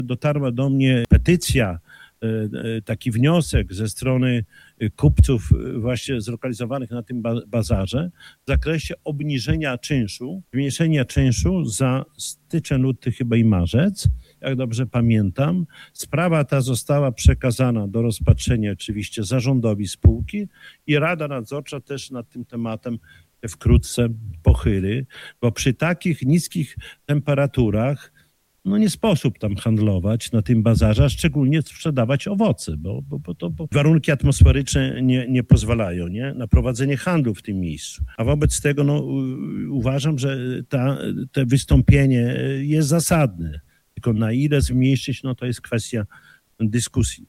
O wystąpieniu handlowców mówił na ostatniej sesji Rady Miasta Czesław Renkiewicz, prezydent Suwałk.